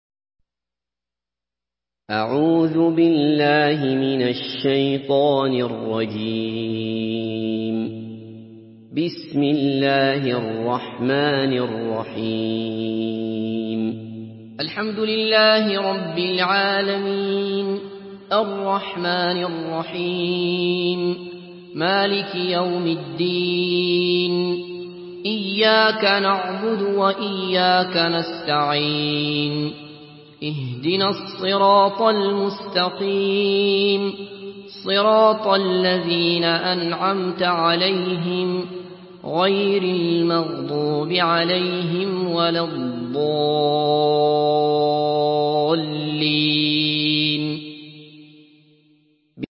Surah আল-ফাতিহা MP3 by Abdullah Basfar in Hafs An Asim narration.
Murattal Hafs An Asim